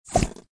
GUI_stickerbook_delete.ogg